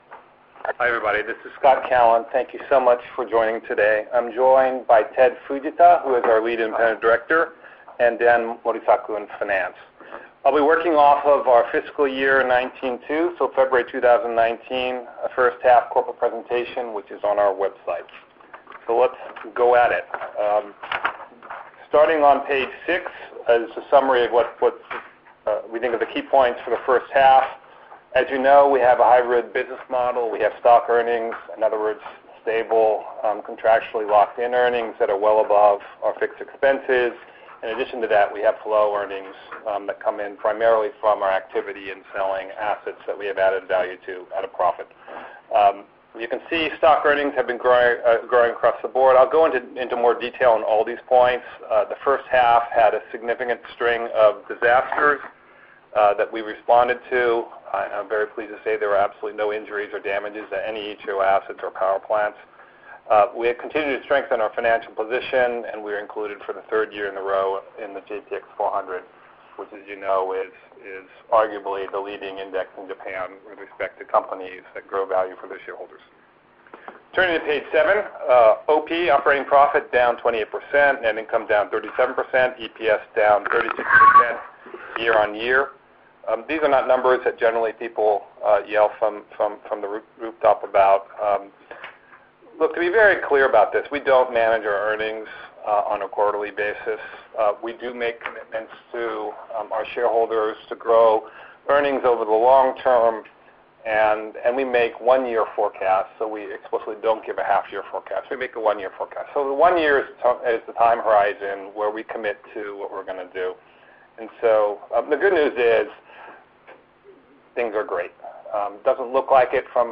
FY19/2 Q1 Earnings Call